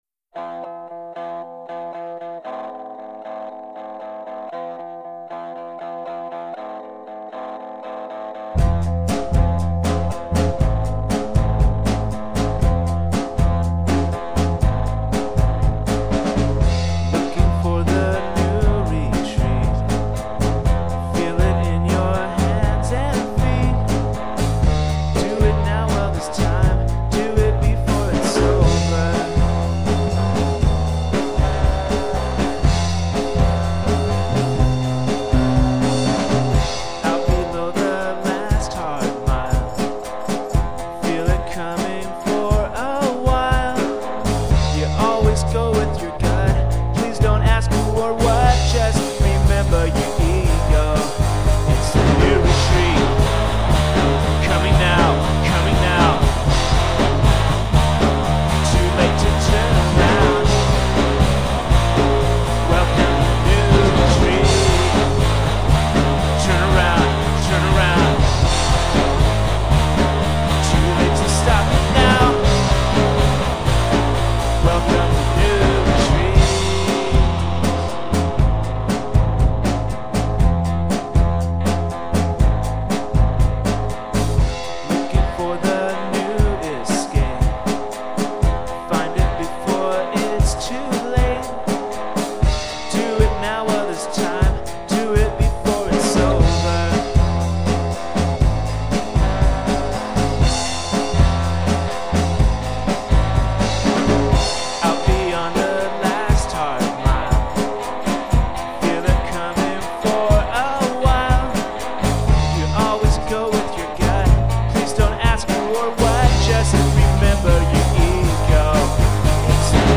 Soothing Audio for Your Modern Lifestyle